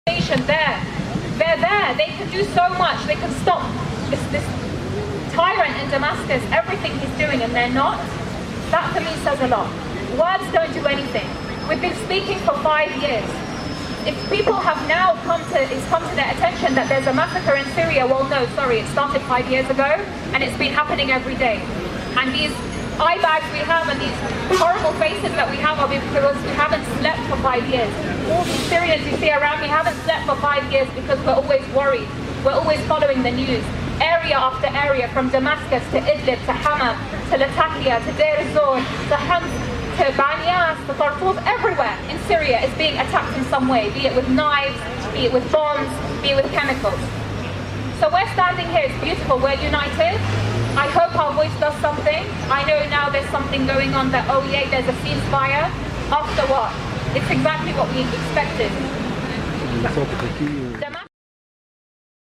'Stop the tyrant in Damascus'： Protesters in London condemn pro-Assad offensive on Aleppo.mp3